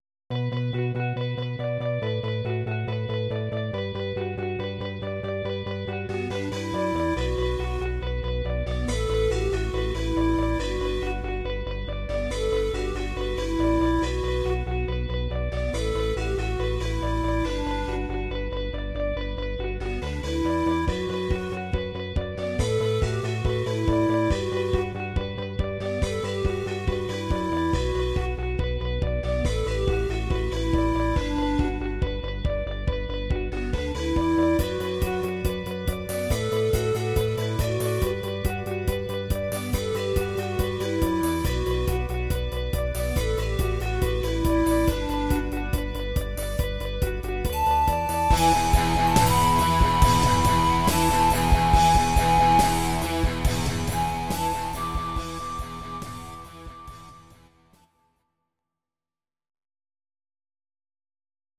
Audio Recordings based on Midi-files
Rock, 1990s